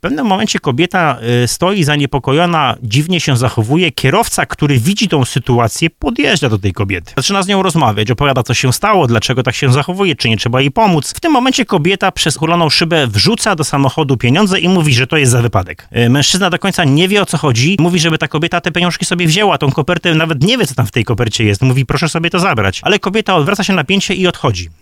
mówił w programie Pomagamy i Chronimy na antenie RDN